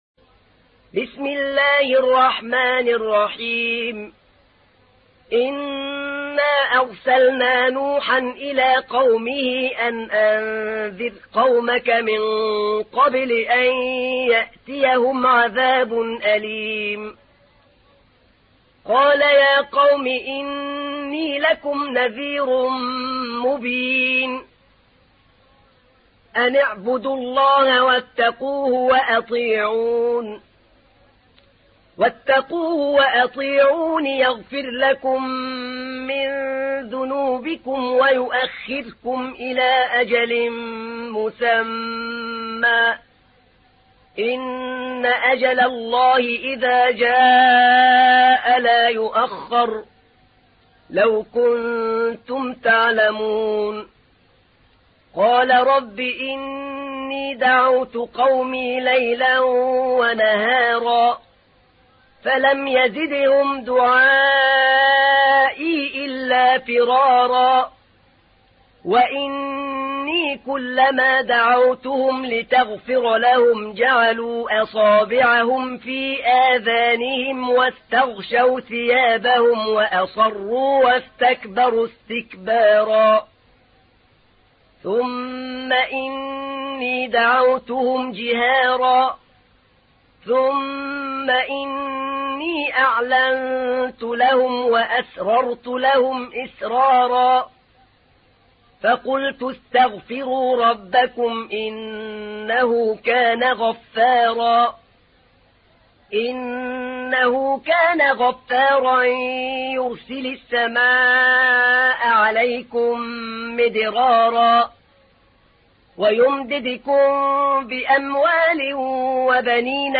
تحميل : 71. سورة نوح / القارئ أحمد نعينع / القرآن الكريم / موقع يا حسين